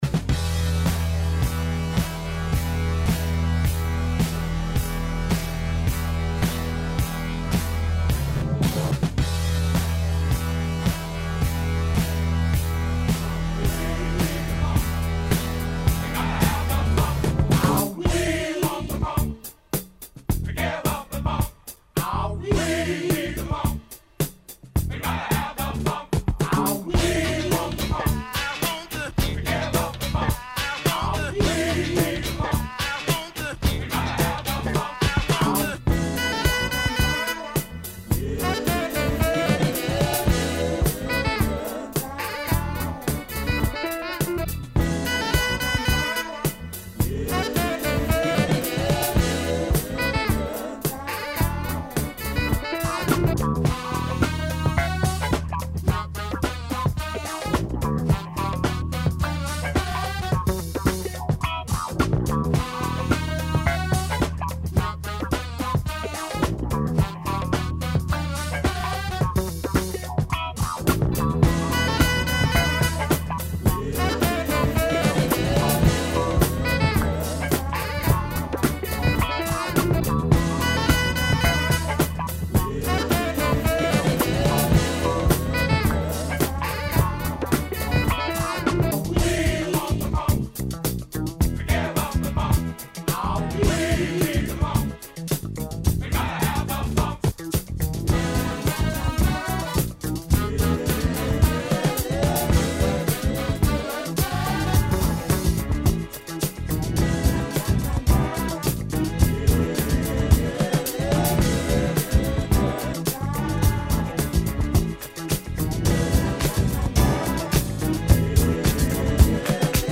This is an original composition made entirely from sound samples no longer than 10 seconds.
Funkin A 108 - 10 funk hits mixed in samples fewer than 10-seconds, all in the Key of A with tempo adjustments to 108 BPM